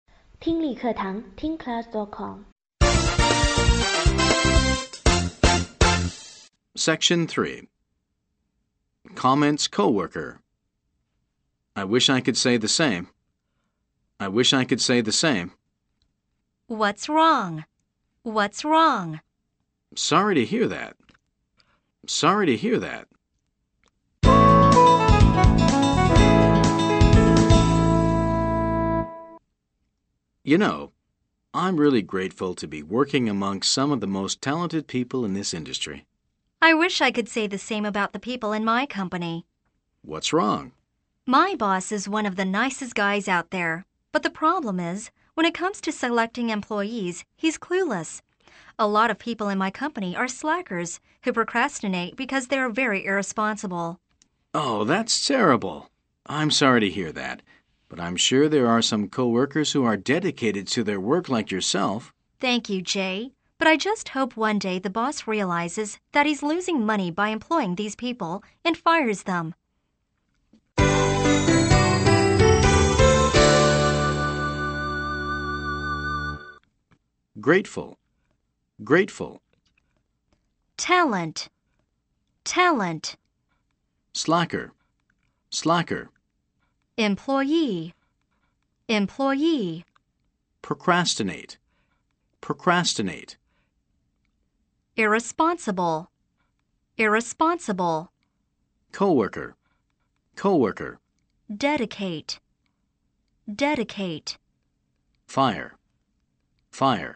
本集英语情境会话